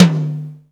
FX-MIX_TOM.wav